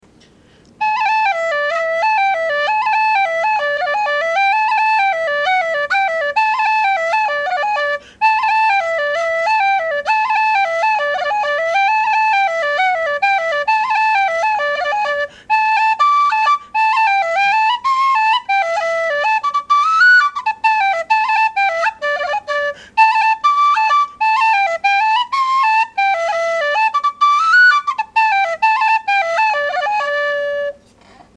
Bottom Line: Strong tone, above average volume. Chiffy, with plenty of backpressure. Holes a bit unevenly drilled.
This whistle had a very strong tone, with a hint of that Feadog-like complexity, and a hint of tweaked-Shaw chiff and windiness.
Sound clip of the whistle:
Volume: A little louder than average.